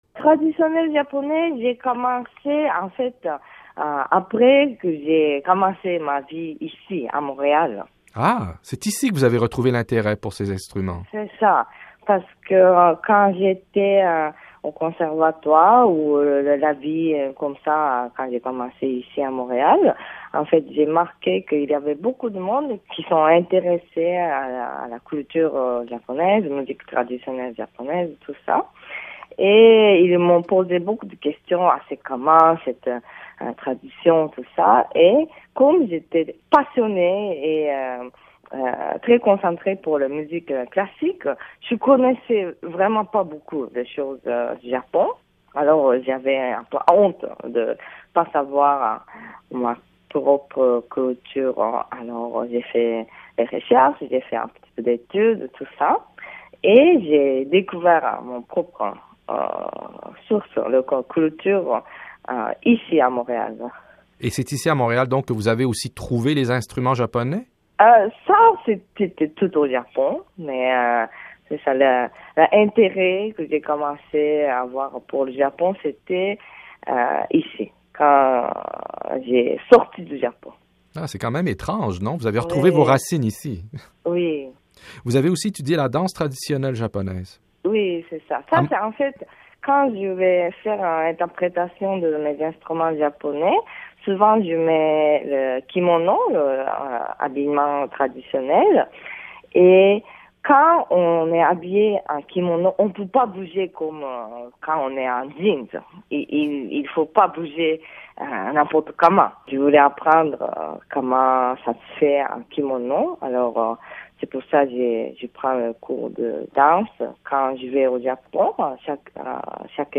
par | Classé dans : Reportages | 0